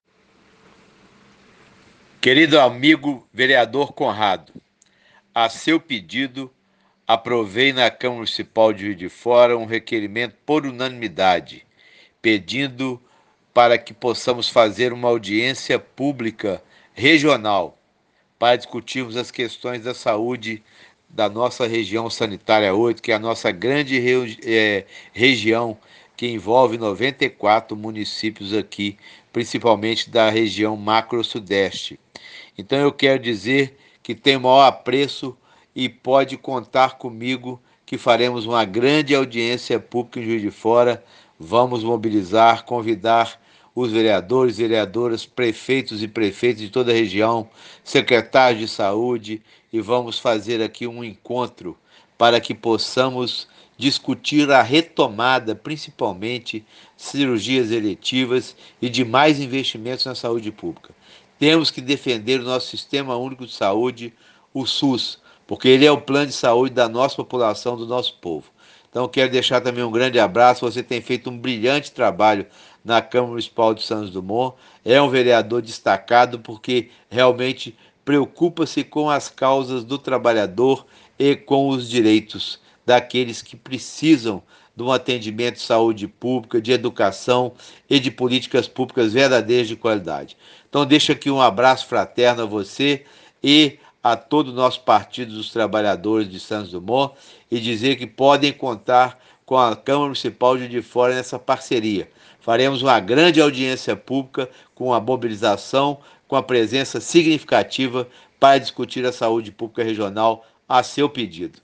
Áudio do Vereador Juraci Sheffer – Presidente da Câmara Municipal de Juiz de Fora